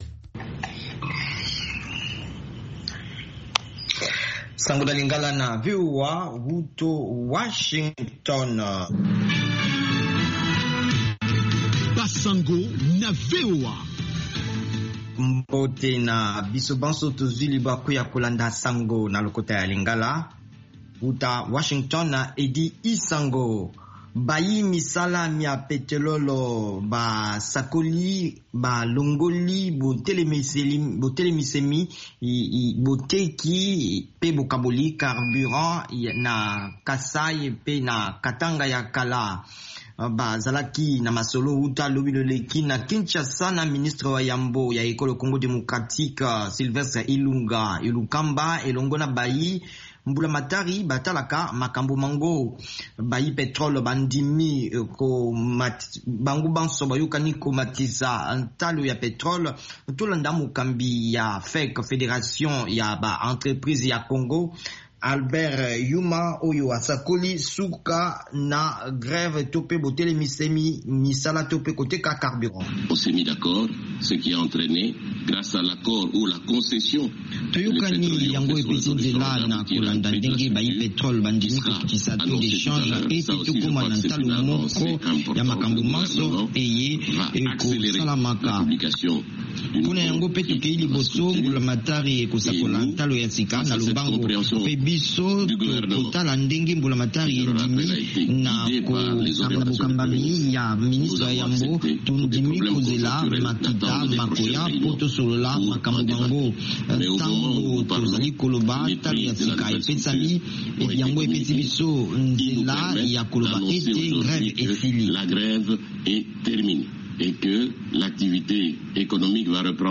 Basango na VOA Lingala